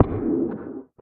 守卫者：受伤
守卫者在水中受伤
Minecraft_guardian_guardian_hit3.mp3